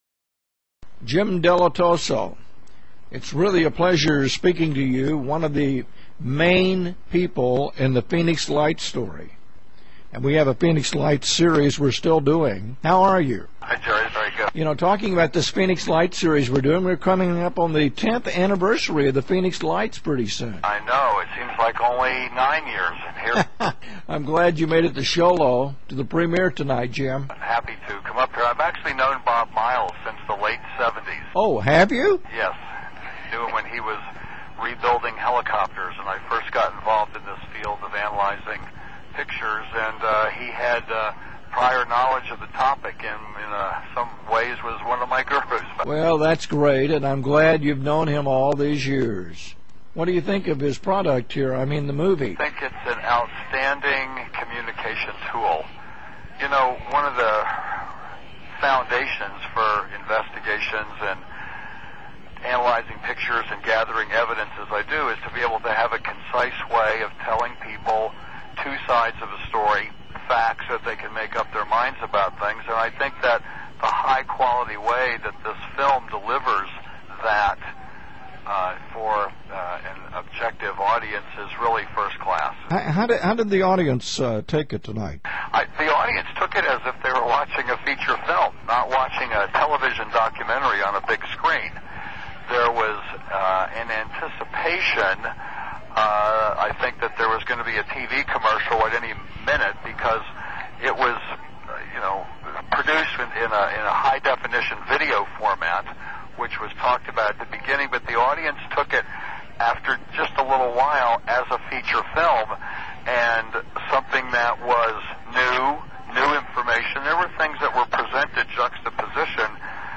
Interview WMA Version